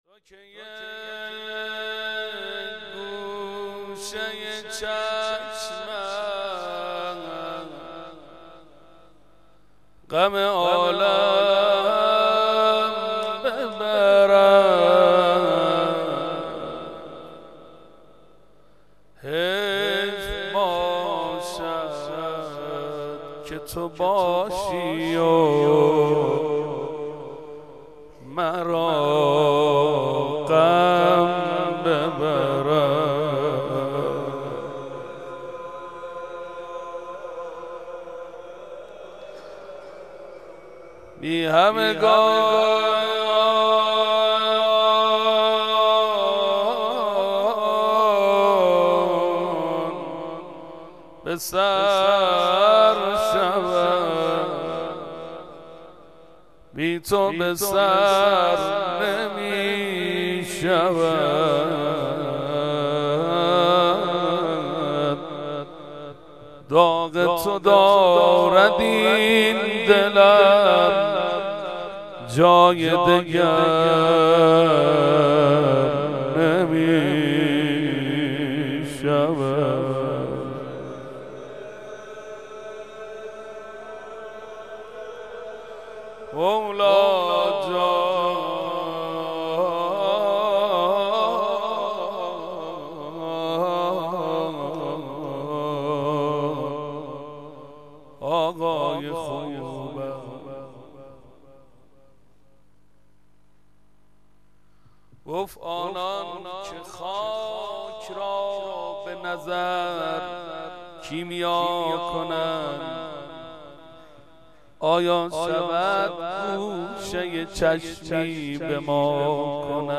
haftegi.93.1.22-madh.mp3